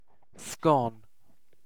Ääntäminen
IPA : /skɒn/ IPA : /skəʊn/